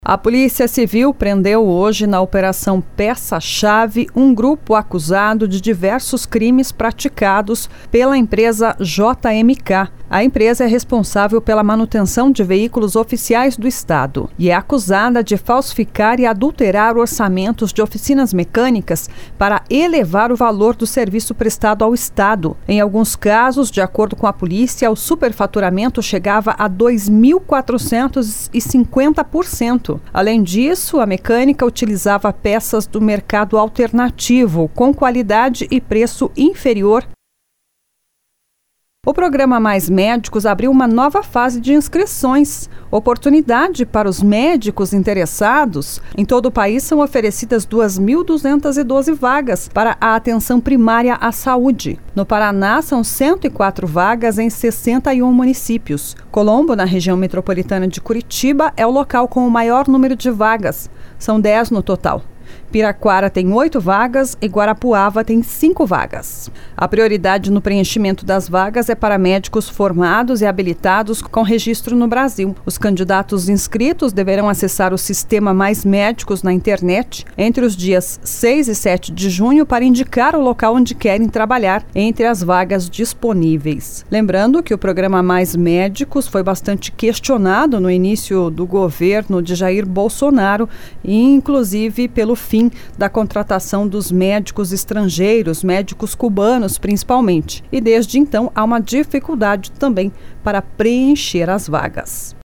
Giro de Notícias SEM TRILHA